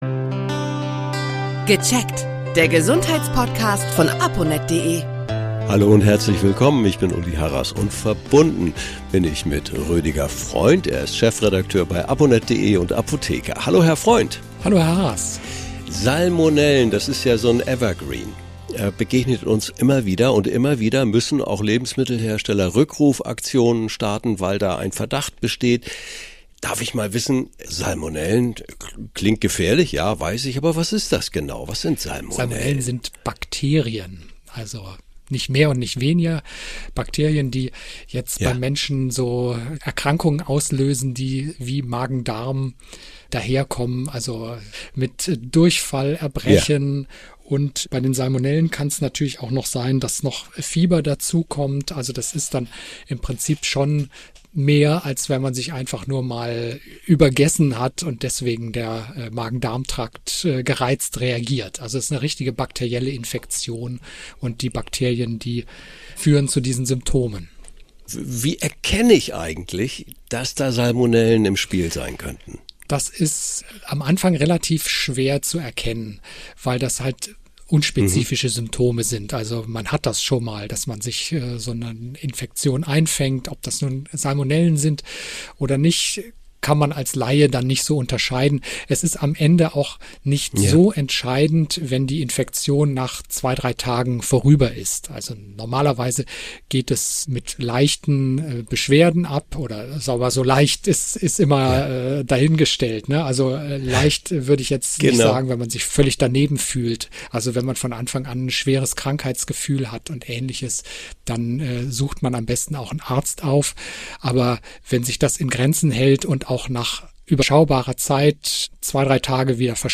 Apotheker